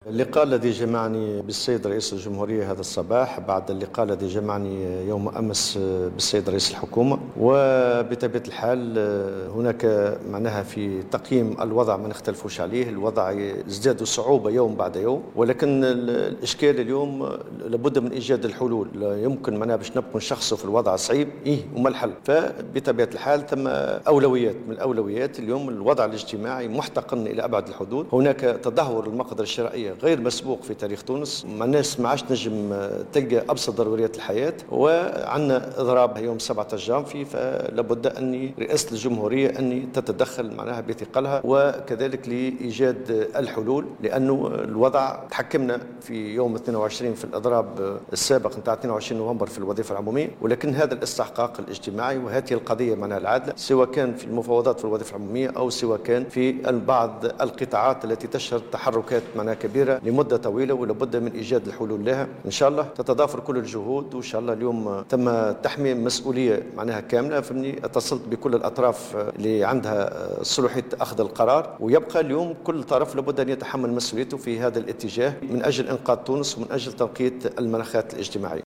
وشدد الطبوبي في تصريح إعلامي، عقب لقاء جمعه برئيس الجمهورية الباجي قائد السبسي، اليوم الأربعاء، على ضرورة أن تتدخل رئاسة الجمهورية بكامل ثقلها قبل تنفيذ الإضراب المقرر في الوظيفة العمومية والقطاع العام يوم 17 جانفي، لإيجاد الحلول اللازمة للتعامل مع الاحتقان الكبير للوضع الاجتماعي ودفع المفاوضات للزيادة في أجور الوظيفة العمومية وبعض القطاعات التي تشهد تحركات منذ مدة.